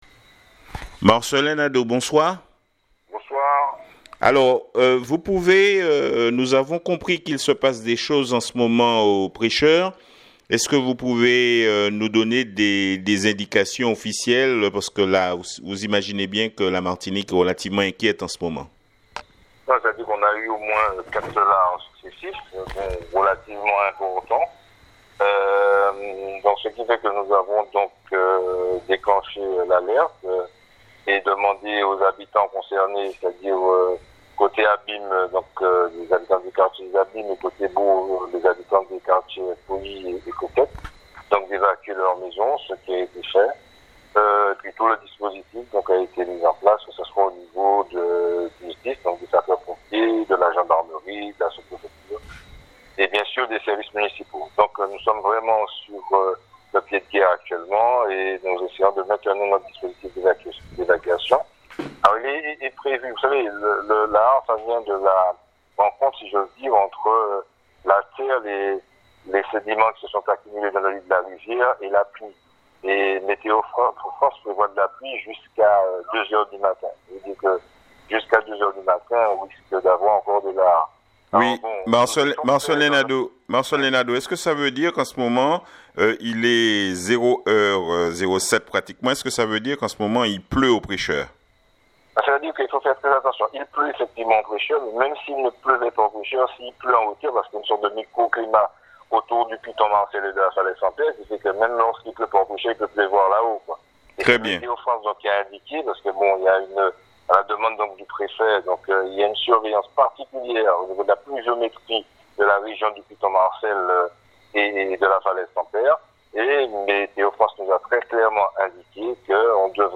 EXCLUSIF ICIMARTINIQUE ITW 0H 15 interview du Maire du Prêcheur Marcelin Nadeau